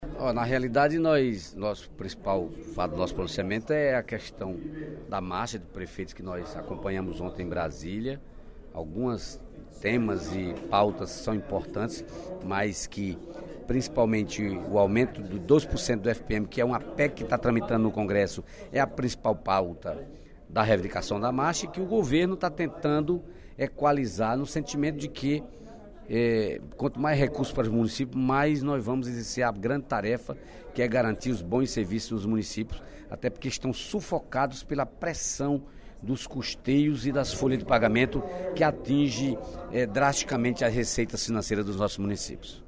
No primeiro expediente da sessão plenária desta quarta-feira (14/05), o deputado Dedé Teixeira (PT) contestou as críticas do deputado João Jaime (DEM) em relação ao Acquario Ceará e os ataques da oposição à gestão do Partido dos Trabalhadores no Governo Federal.